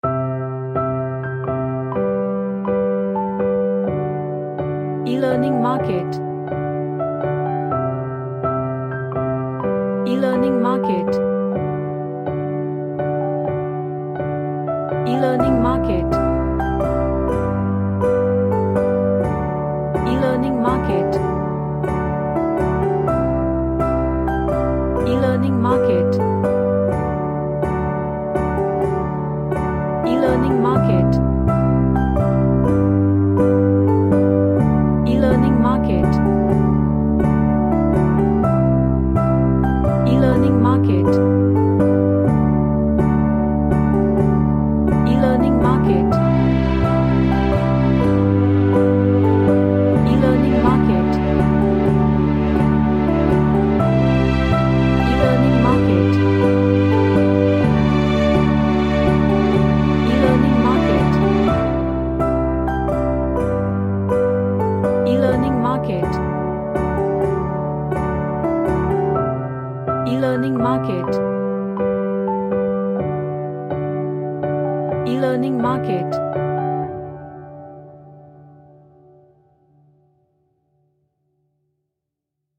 An emotional track with modular synths
Gentle / Light